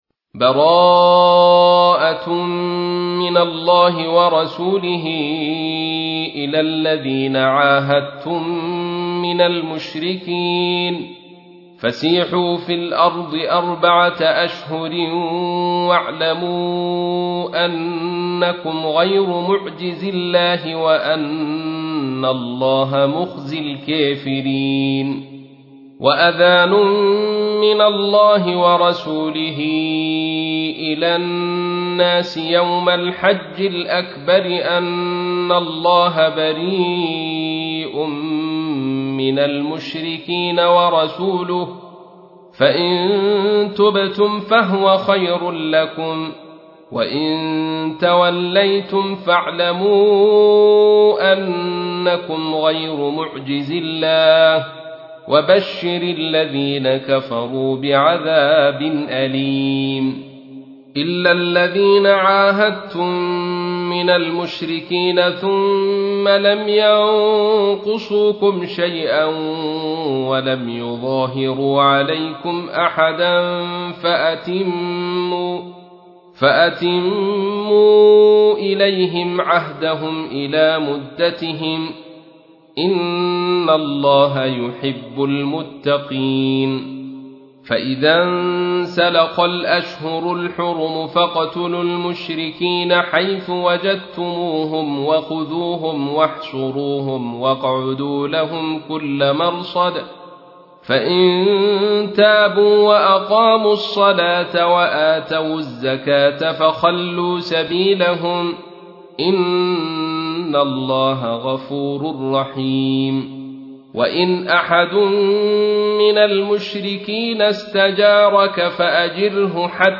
تحميل : 9. سورة التوبة / القارئ عبد الرشيد صوفي / القرآن الكريم / موقع يا حسين